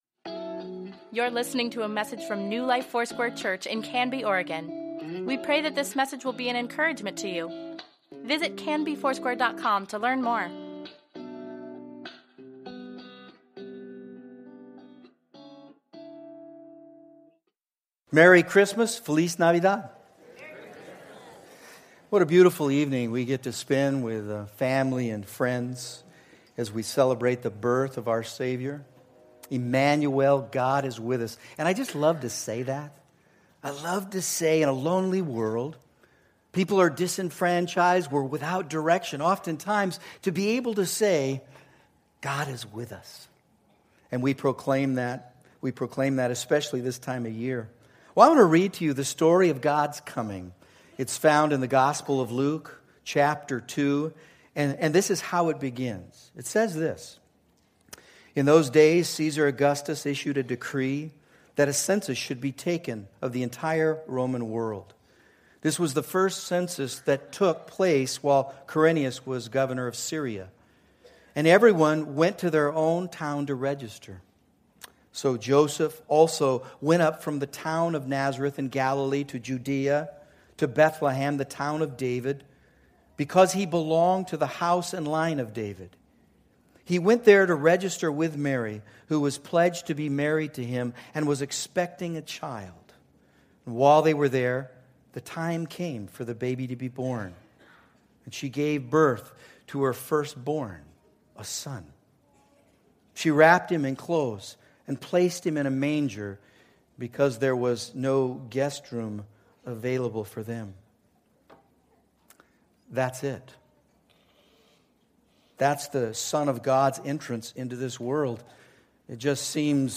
Weekly Email Water Baptism Prayer Events Sermons Give Care for Carus All I Want for Christmas is Peace December 24, 2016